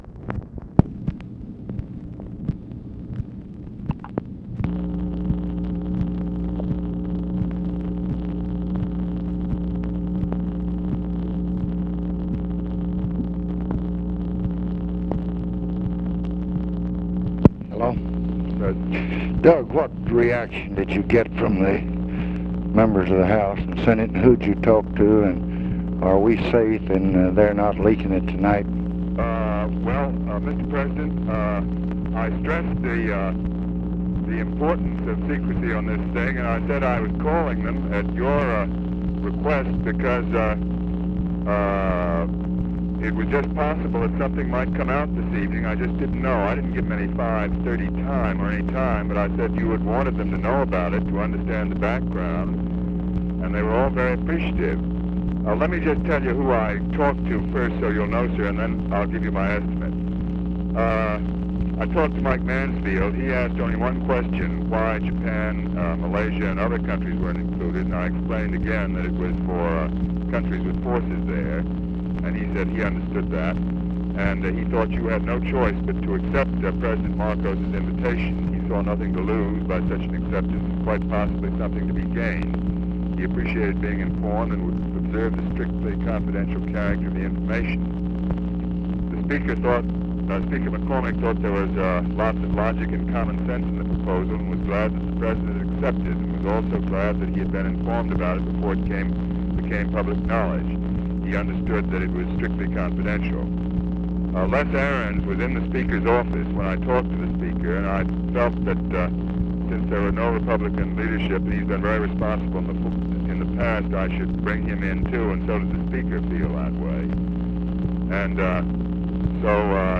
Conversation with DOUGLAS MACARTHUR, September 26, 1966
Secret White House Tapes